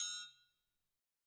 Triangle3-HitM_v2_rr1_Sum.wav